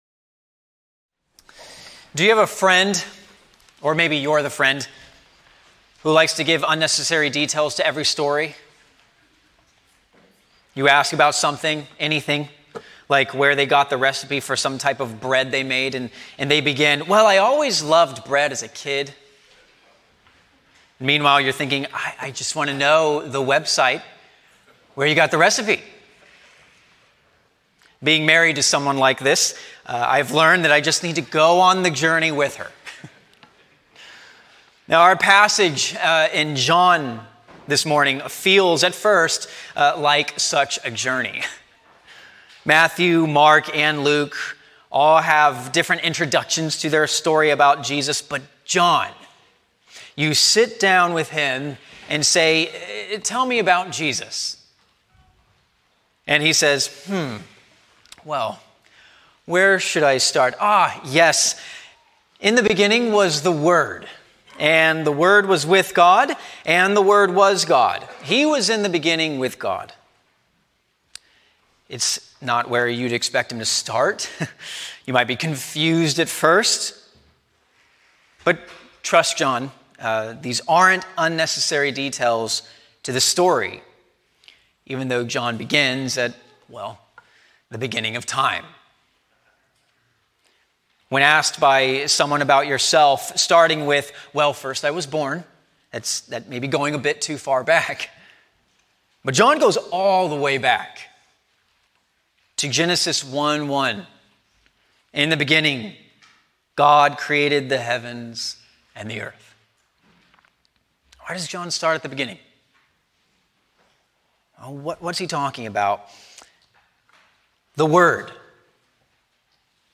A sermon on John 1:1-18